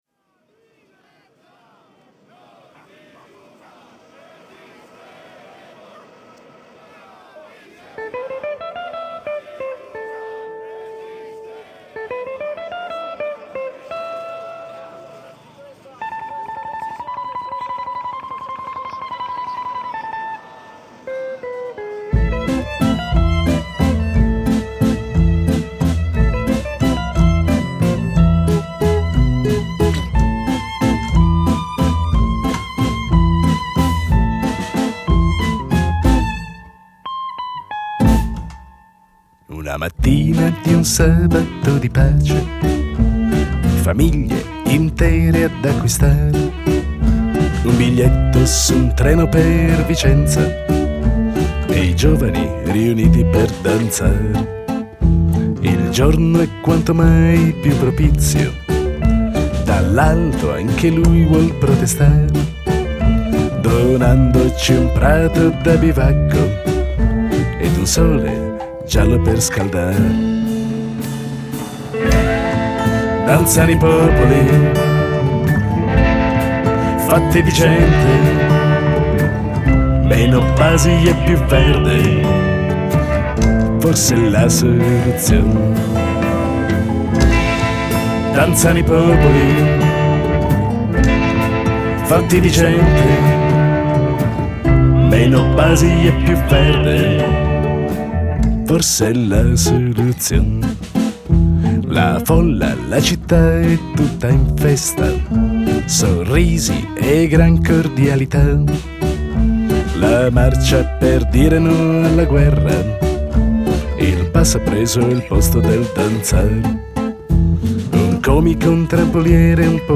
GenereWorld Music / Musica Popolare